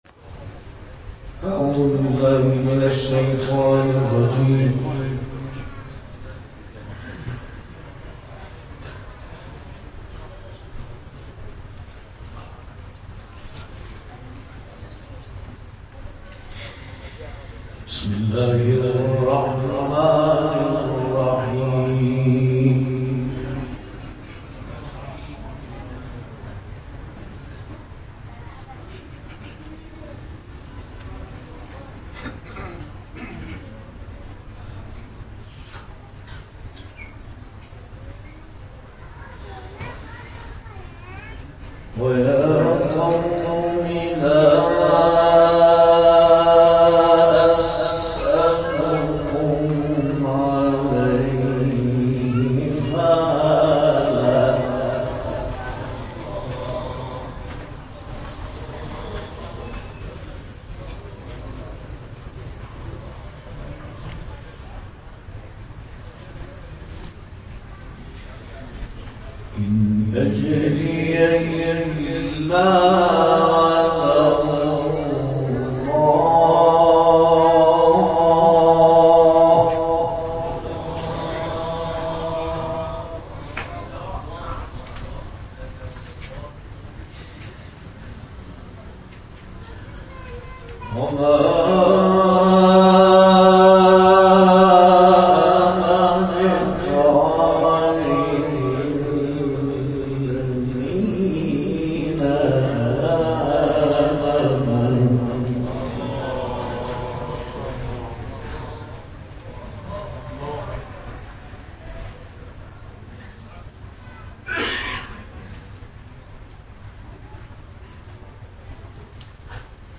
گروه فعالیت‌های قرآنی: کرسی تلاوت قرآن به مناسبت عید غدیر خم و اولین سالگرد شهدای فاجعه منا و شهدای قرآنی به همت موسسه قرآنی پیروان شهدای کربلا برگزار شد.
به گزارش خبرگزاری بین‌المللی قرآن (ایکنا)، کرسی تلاوت به مناسبت اولین سالگرد شهدای فاجعه منا و شهدای قرآنی و همزمان با عید غدیر خم به همت موسسه قرآنی پیروان شهدای کربلا، دوشنبه، ۲۹ شهریور از نماز مغرب و عشاء در حسینیه پیروان شهدا کربلا واقع در شهر ری، خیابان شهید غیوری، بالاتر از چهارراه خط آهن برگزار شد.